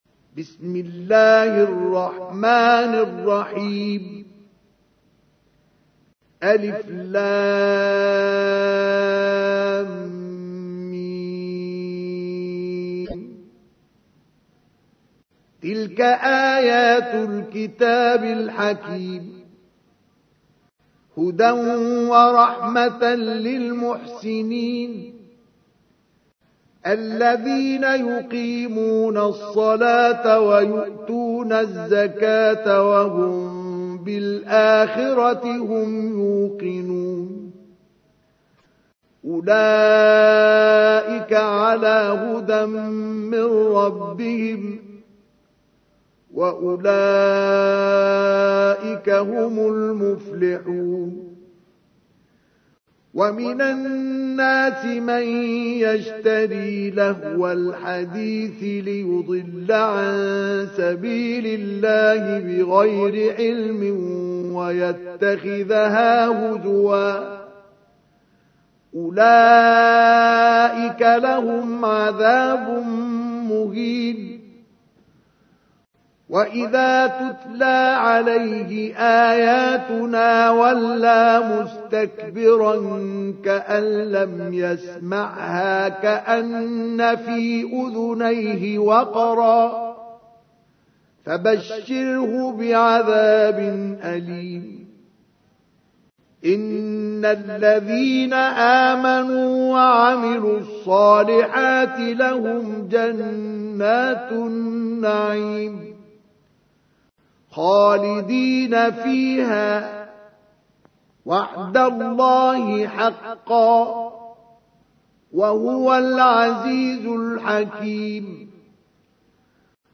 تحميل : 31. سورة لقمان / القارئ مصطفى اسماعيل / القرآن الكريم / موقع يا حسين